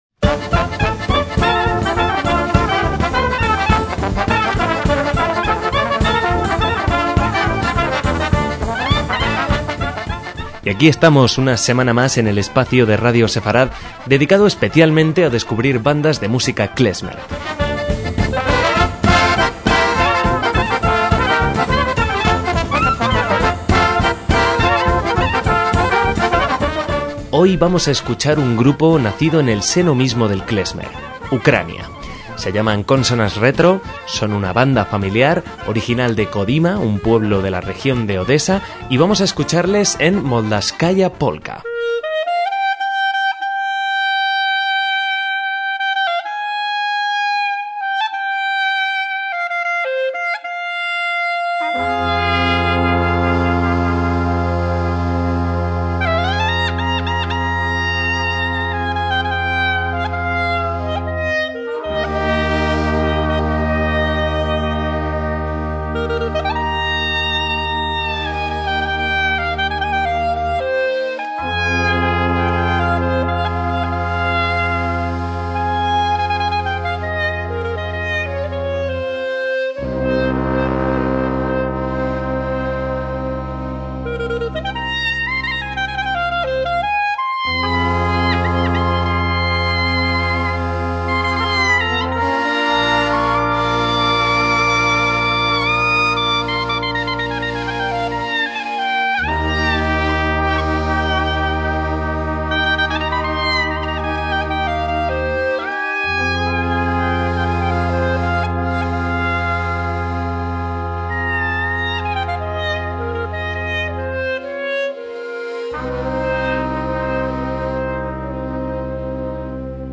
MÚSICA KLEZMER
banda de metales ucraniana
trompeta
acordeón
bombo